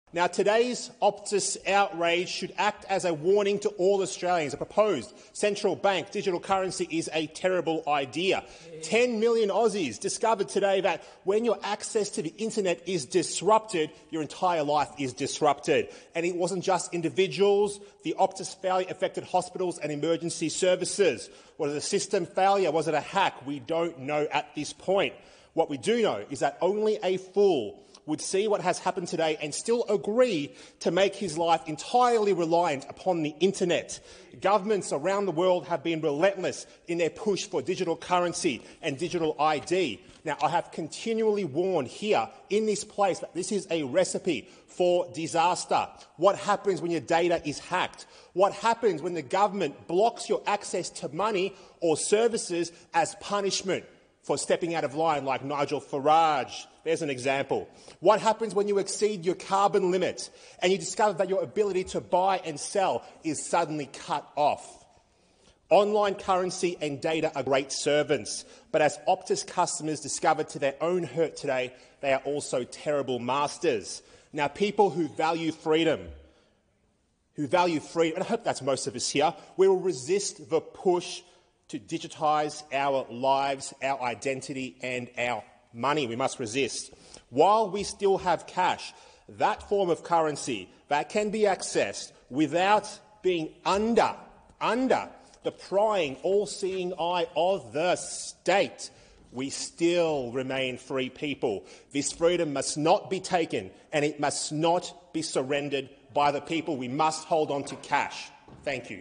🚨הסנאטור האוסטרלי ראלף באבט, מעל בימת הסנאט